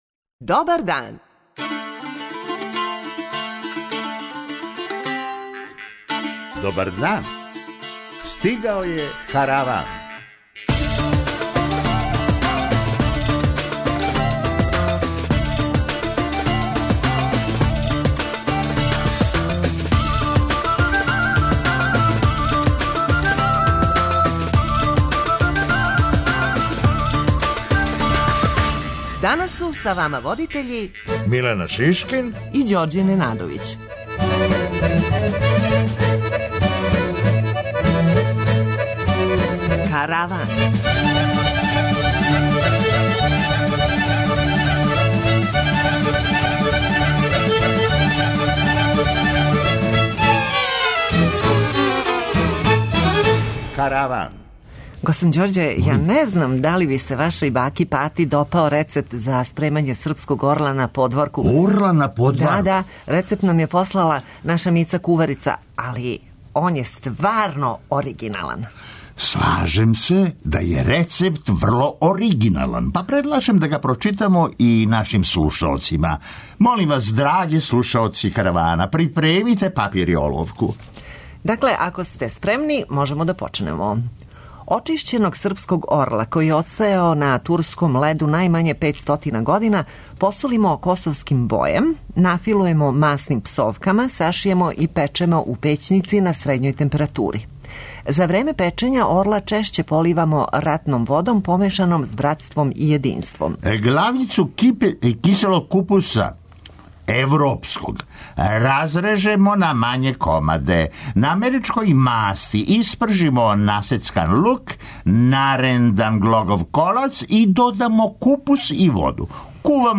[ детаљније ] Све епизоде серијала Аудио подкаст Радио Београд 1 Подстицаји у сточарству - шта доносе нове мере Хумористичка емисија Хумористичка емисија Корак ка науци Афера Епстин "не пушта" британског премијера